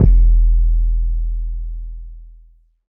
BasicB808_YC.wav